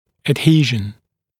[əd’hiːʒən][эд’хи:жэн]прилипание, слипание; адгезия; спайка